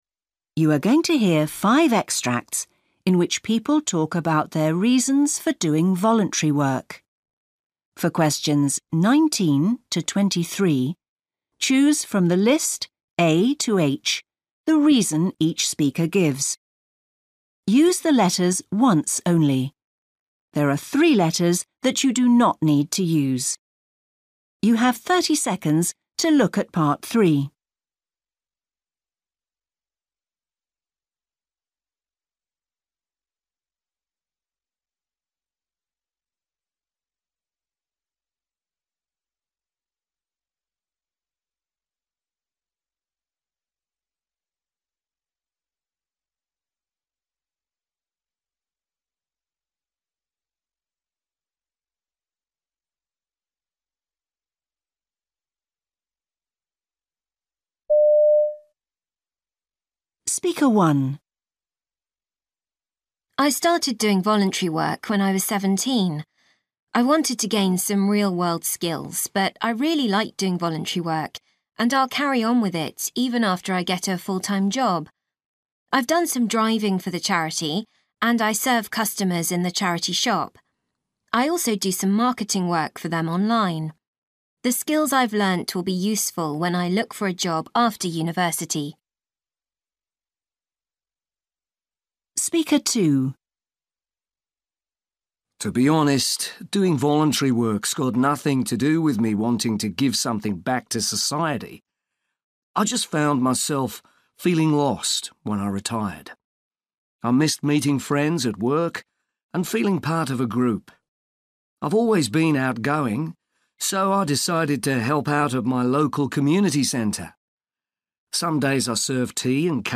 You are going to hear five extracts in which people talk about their reasons for doing voluntary work.